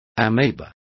Complete with pronunciation of the translation of ameba.